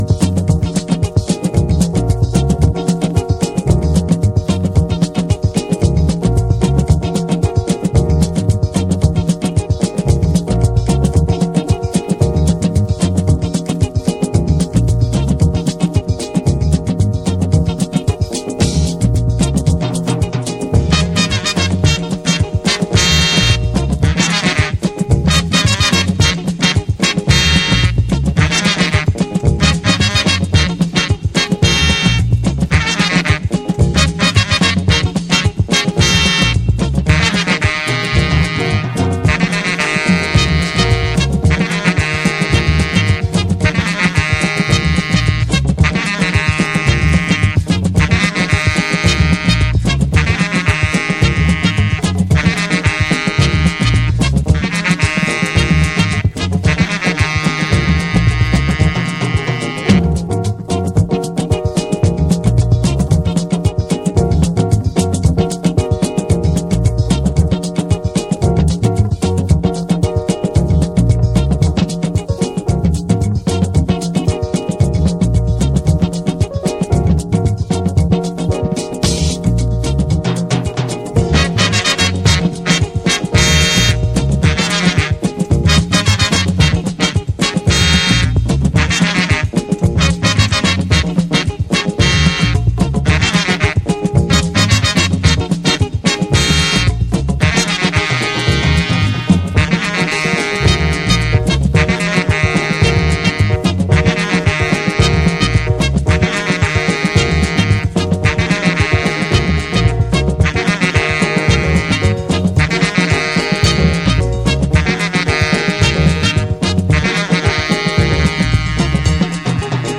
afro-beat gems